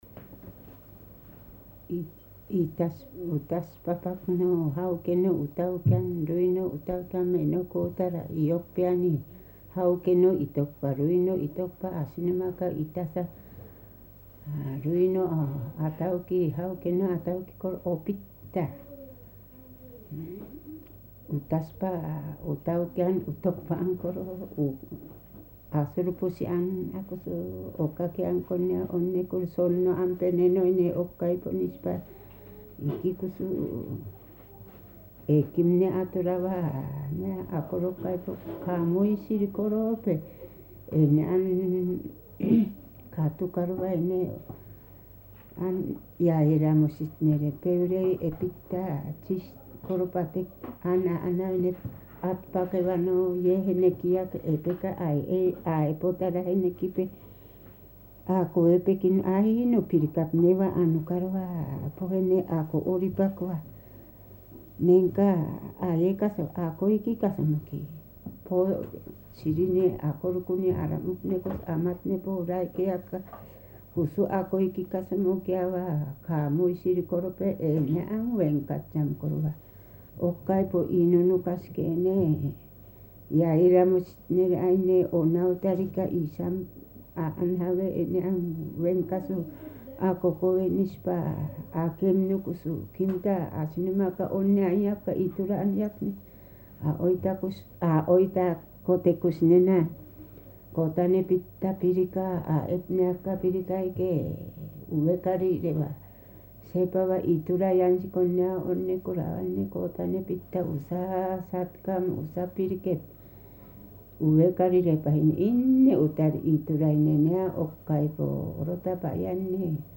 [19-2 散文説話 prose tales]【アイヌ語】9:12